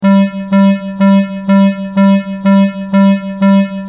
Warning Alarm Loop